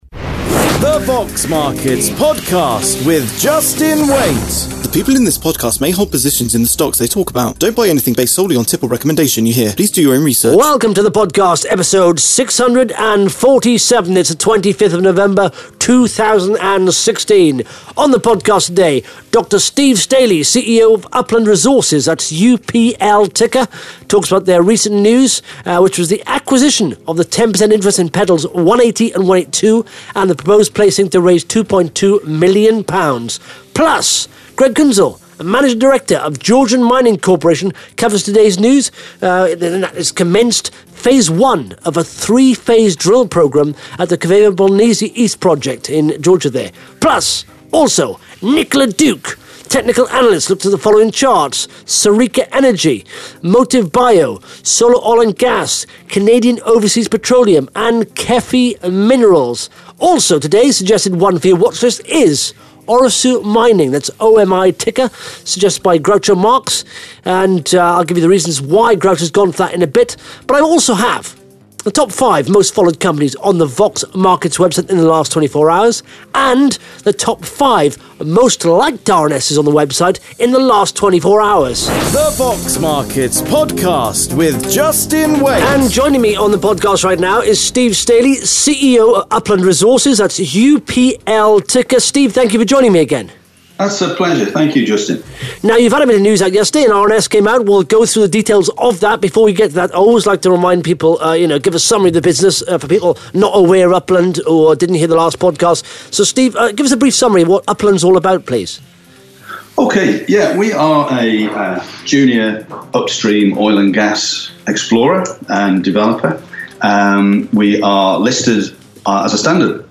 (Interview starts at 1 minutes 22 seconds)